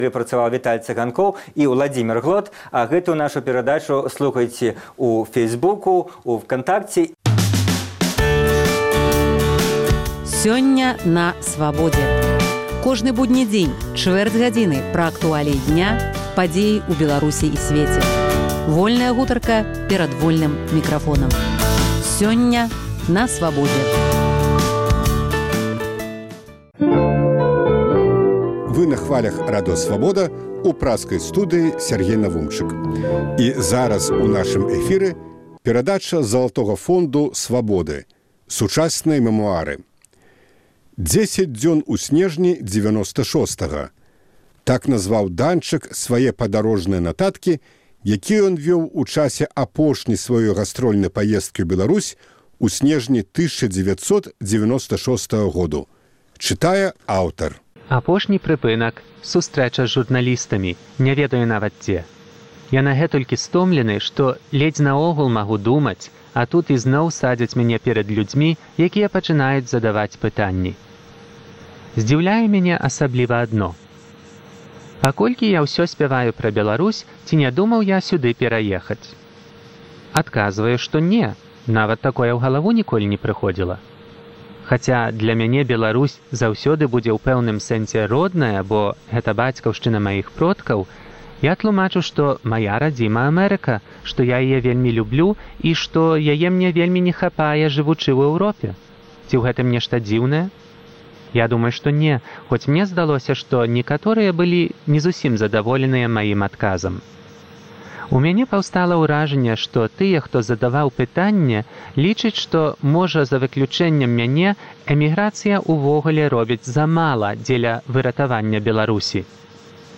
У чытальнай залі Свабоды - успаміны ў аўтарскім чытаньні.